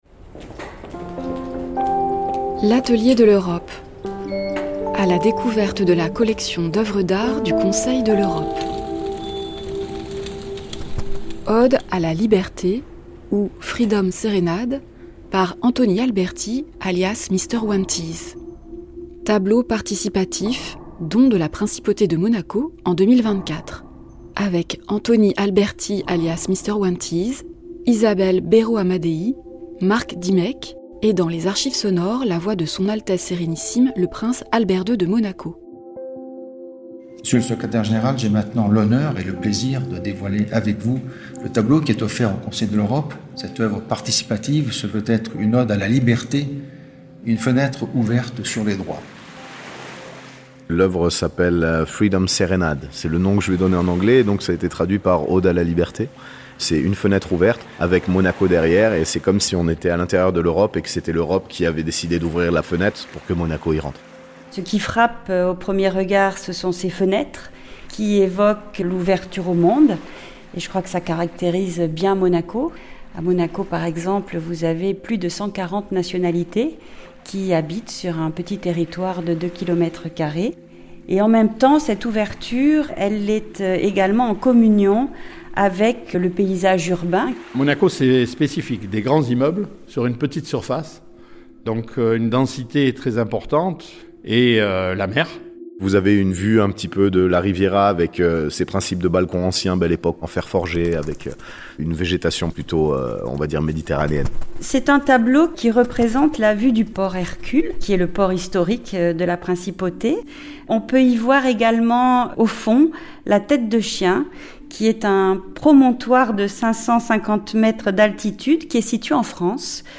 Isabelle Berro-Amadeï, conseillère de Gouvernement - ministre des Relations Extérieures et de la Coopération de la Principauté de Monaco, ancienne juge à la Cour européenne des droits de l’homme (2006-2012)